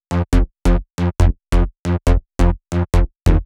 VTS1 Another Day Kit Bassline